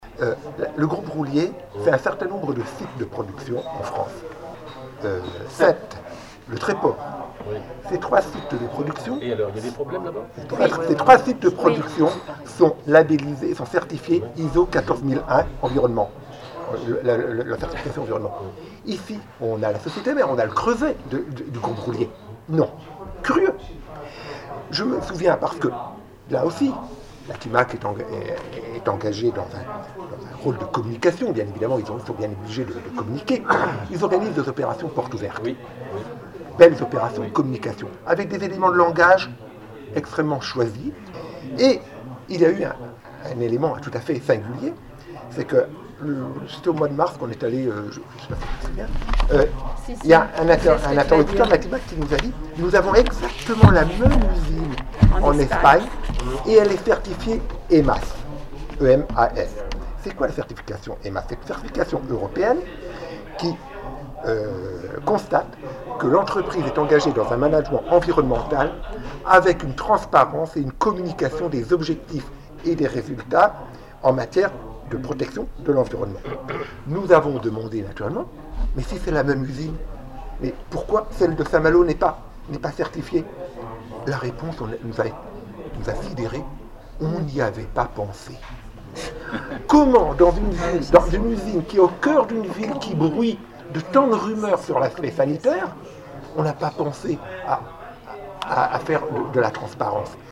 Pas plus que nous, la preuve, malgré le bruit de fond, nous vous proposons d’écouter les extraits de notre conférence de presse de début septembre 2019 :